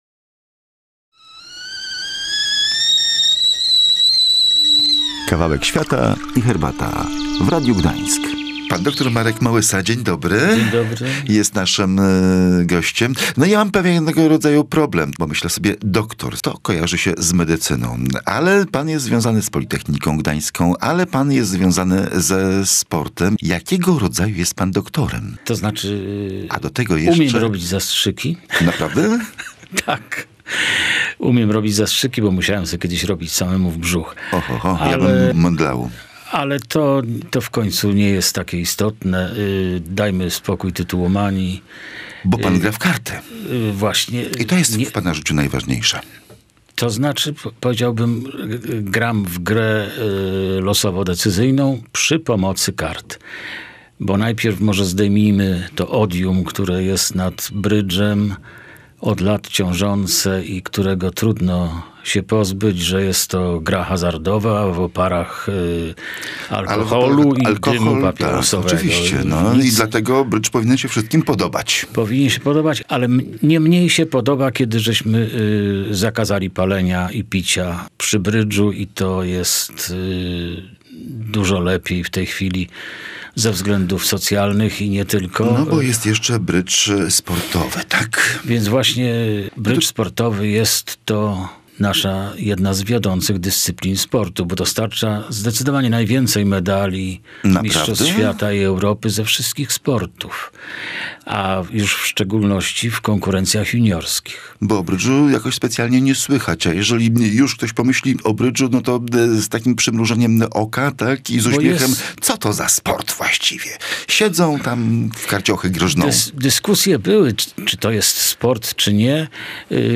Dlaczego brydż ma pozytywny wpływ na funkcjonowanie mózgu? Posłuchaj rozmowy